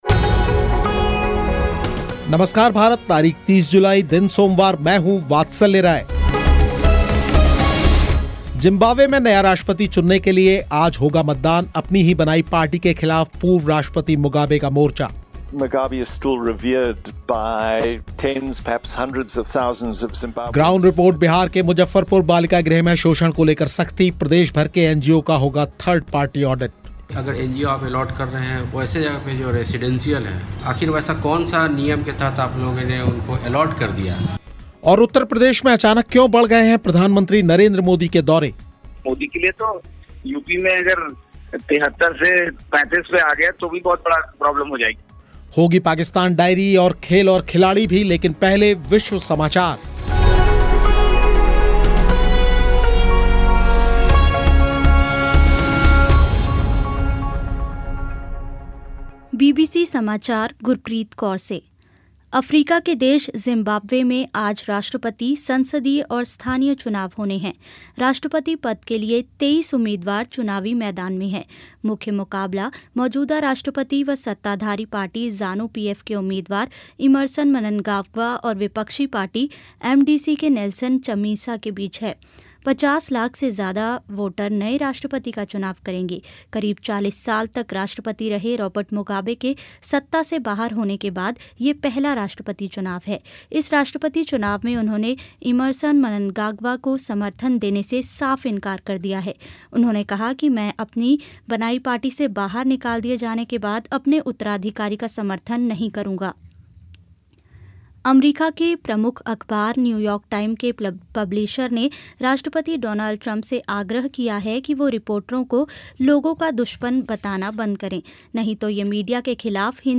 ग्राउंड रिपोर्ट, बिहार के मुज़फ़्फ़रपुर बालिका गृह में शोषण को लेकर सख्ती, प्रदेश भर के NGO का होगा थर्ड पार्टी ऑडिट